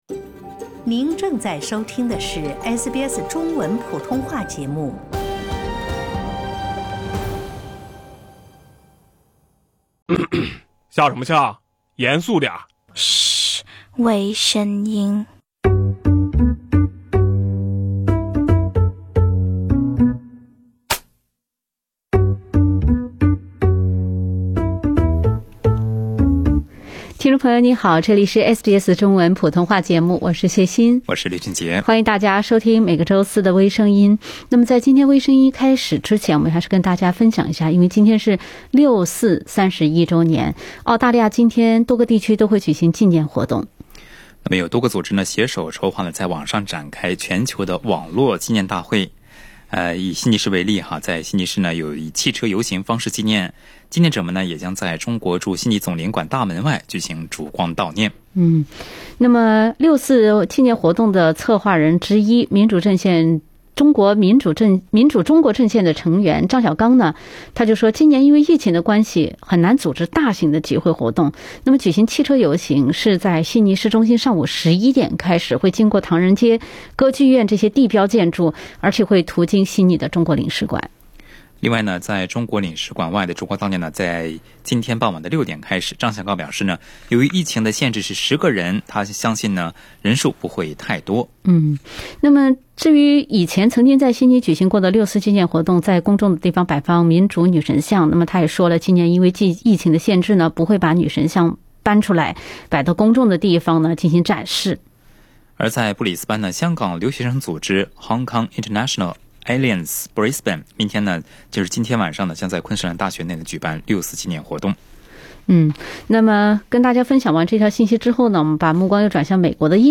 另类轻松的播报方式，深入浅出的辛辣点评，包罗万象的最新资讯，点击文首图片，倾听全球微声音。